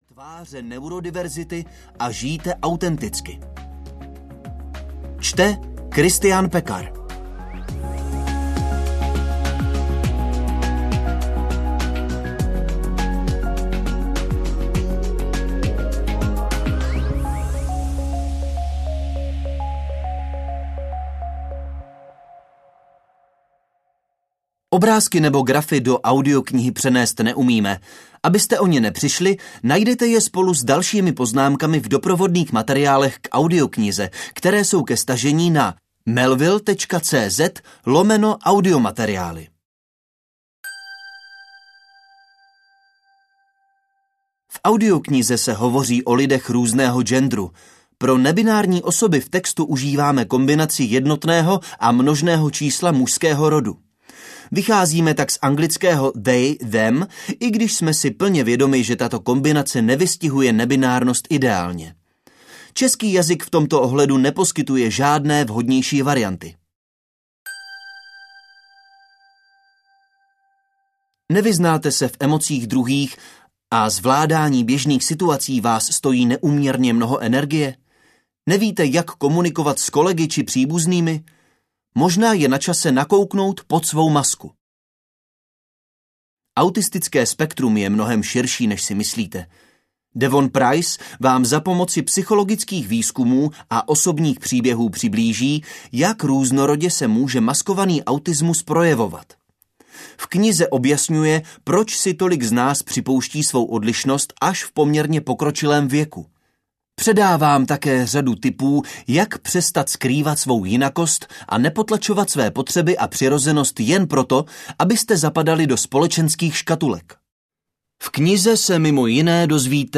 Autismus bez masky audiokniha
Ukázka z knihy